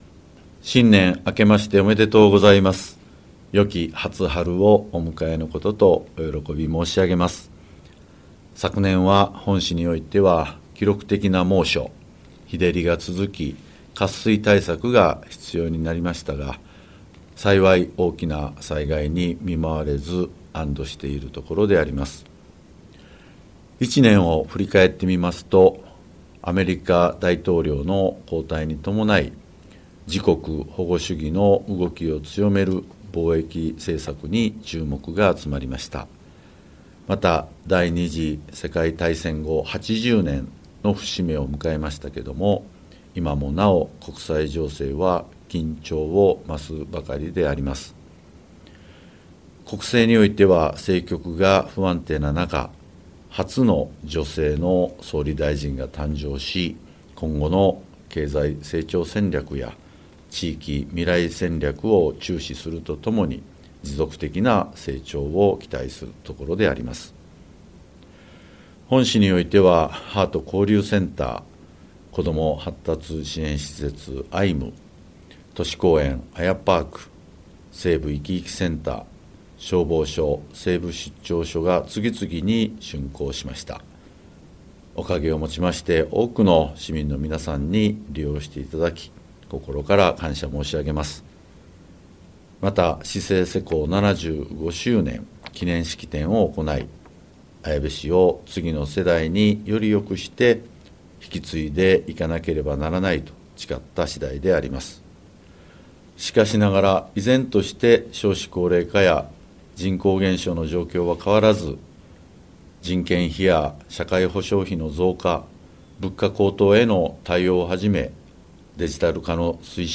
2026年市長年頭あいさつ | 綾部市